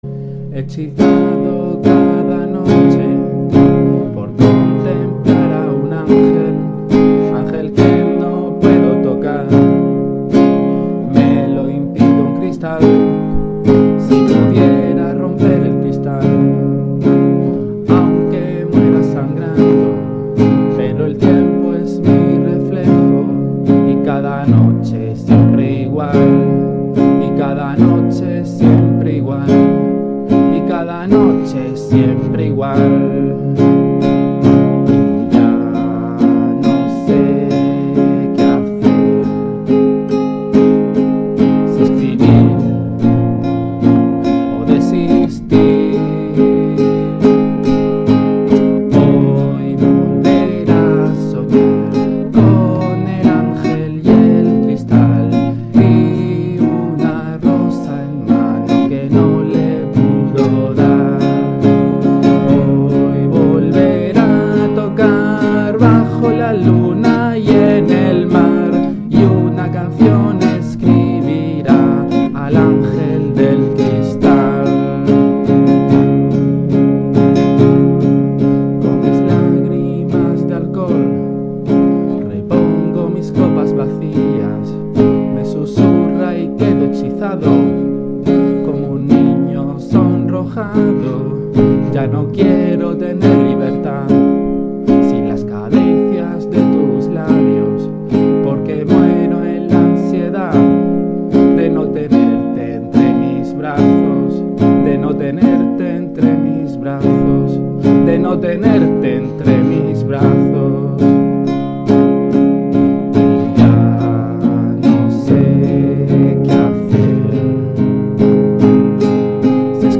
Dejo un audio casero de una de las primeras canciones que escribí para una chica. El audio es una mierda pero no tenía más medios que un micrófono viejo de PC, y es sólo un ensayo de guitarra con voz.